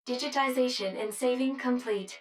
153_Saving_Complete.wav